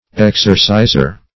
exerciser - definition of exerciser - synonyms, pronunciation, spelling from Free Dictionary
exerciser \ex"er*ci`ser\, n.